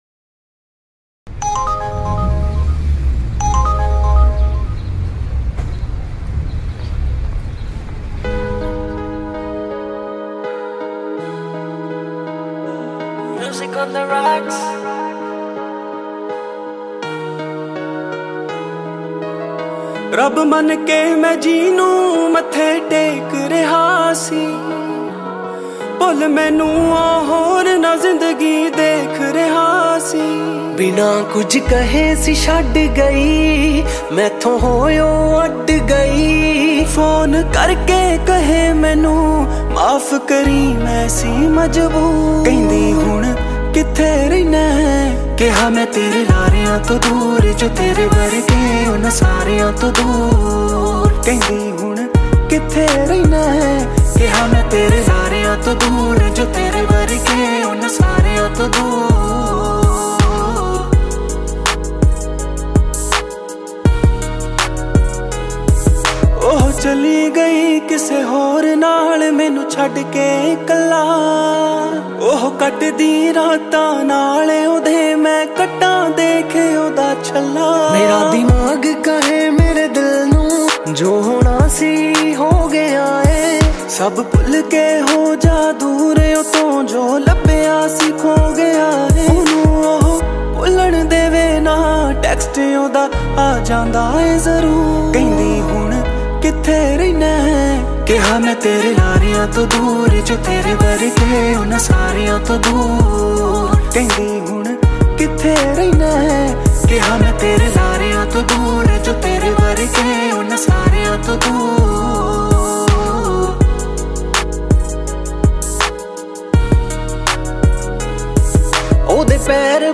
Punjabi Single Track